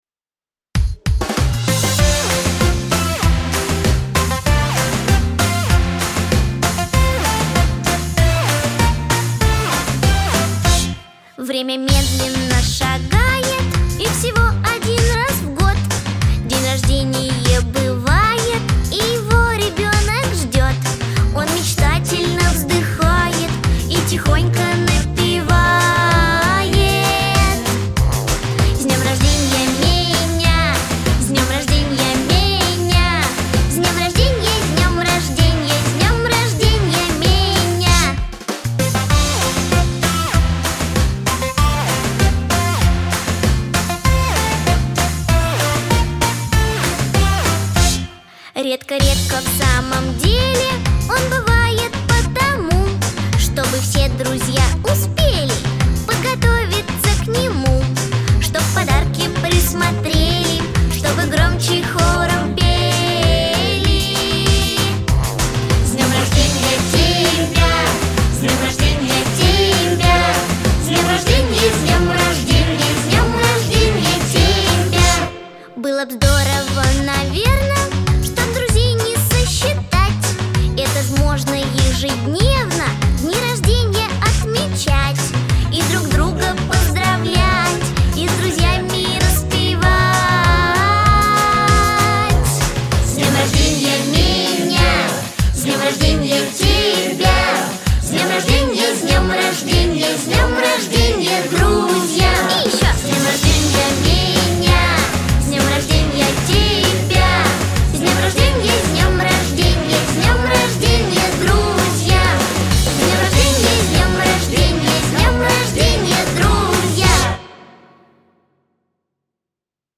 Жанр: Forchildren